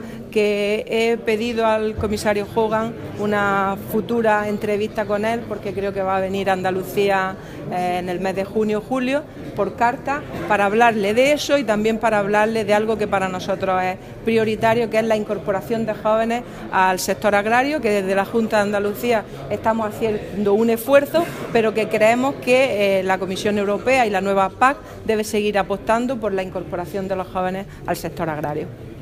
Declaraciones de Carmen Ortiz sobre la PAC post 2020